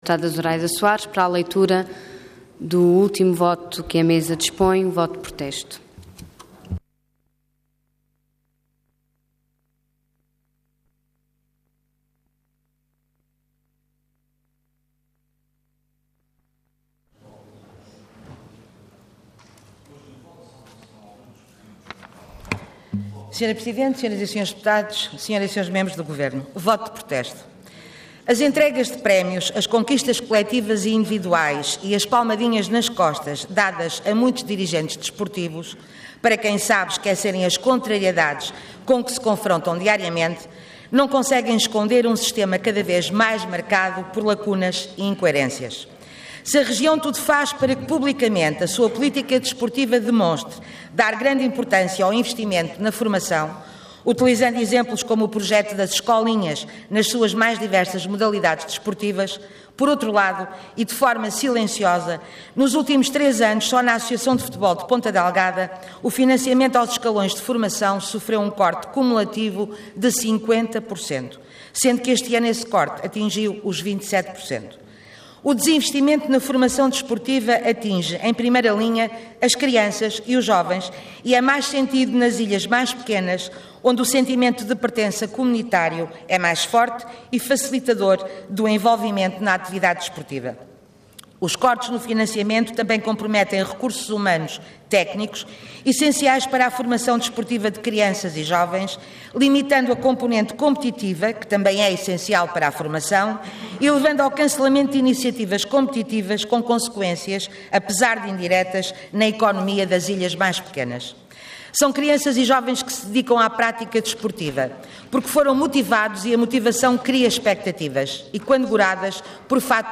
Intervenção Voto de Protesto Orador Zuraida Soares Cargo Deputada Entidade BE